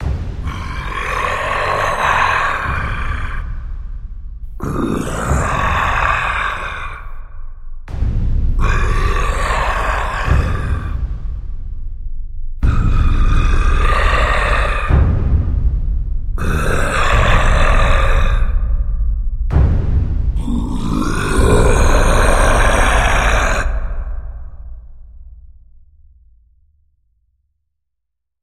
Грозный рык Кинг Конга (огромный монстр)